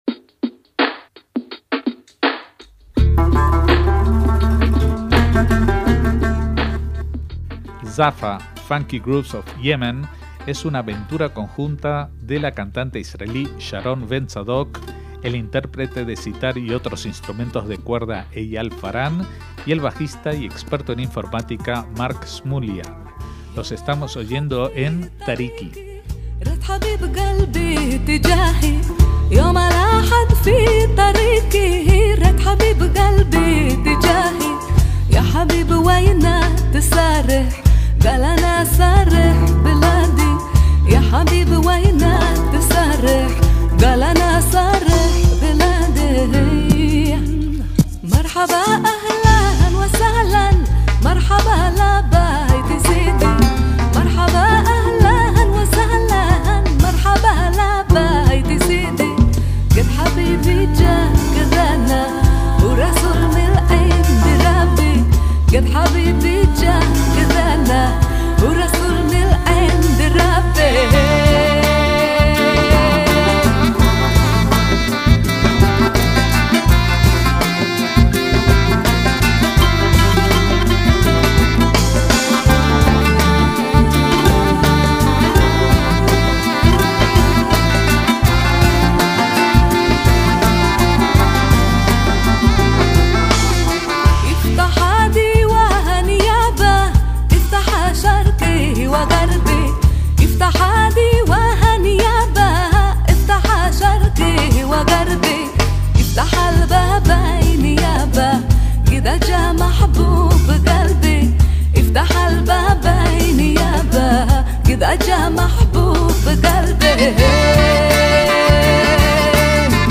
La cantante israelí